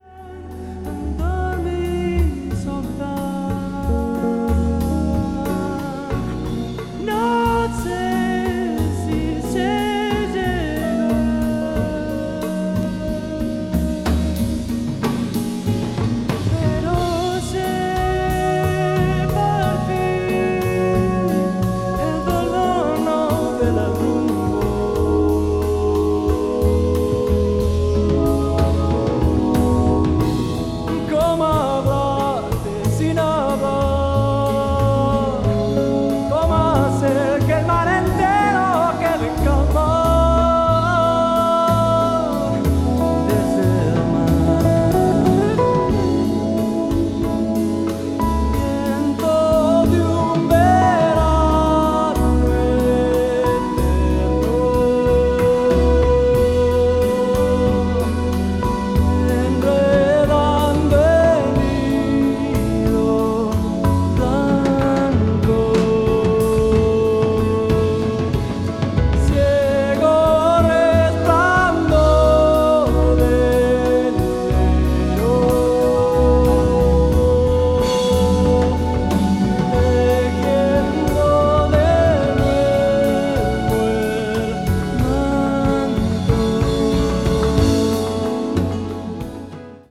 contemporary jazz   crossover   fusion   new age jazz